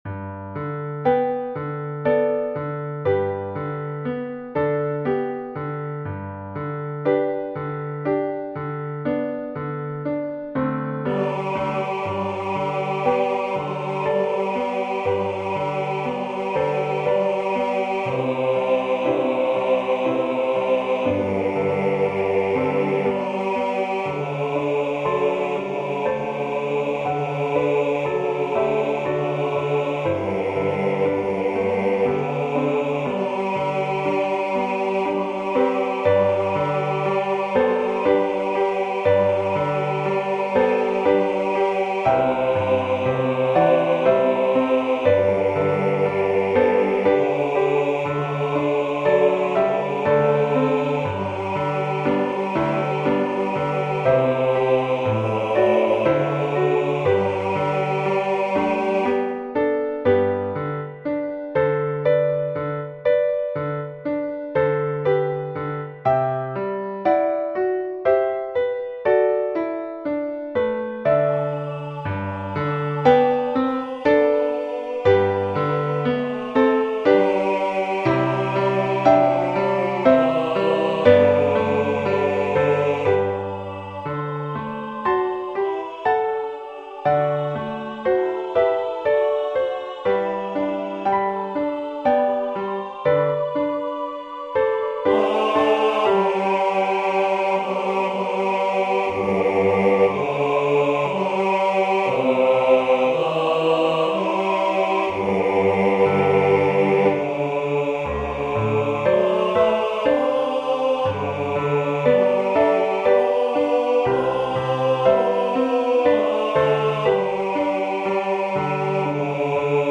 Bass
Away_in_a_Manager_AliceParker_Bass.mp3